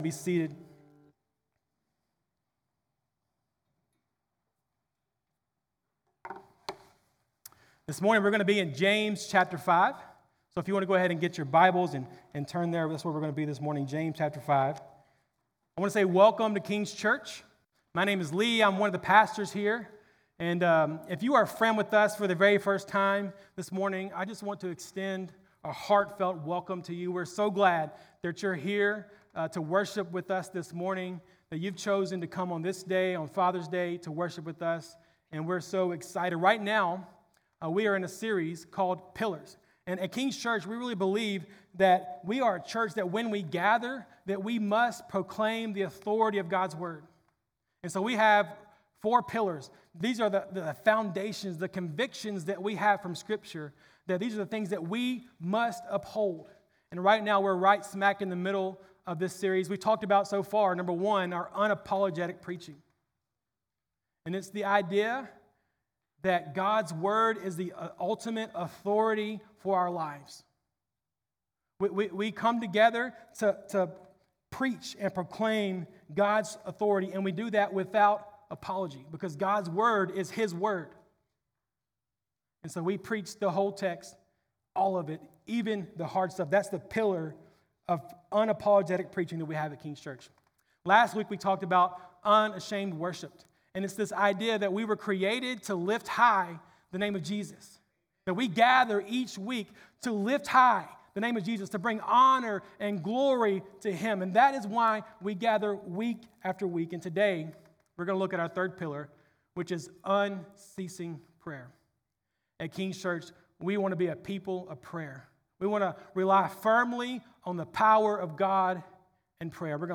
June 15 Sermon.mp3